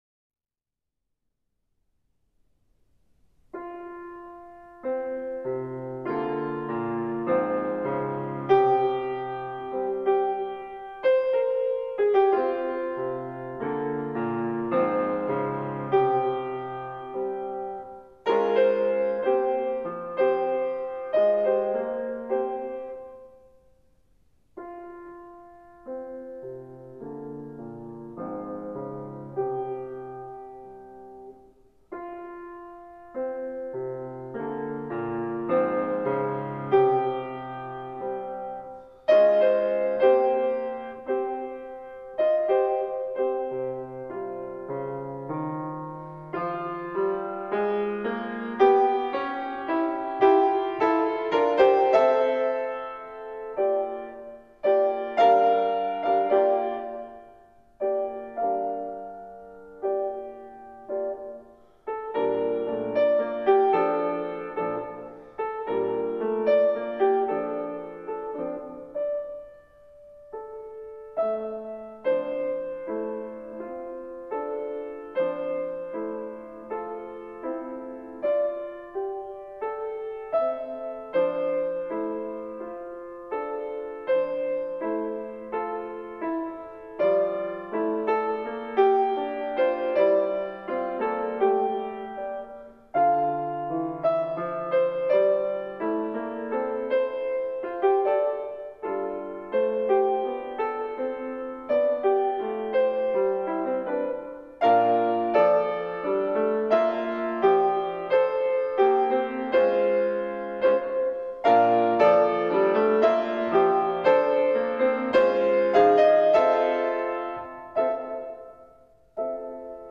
Here you may download the entire piano transcription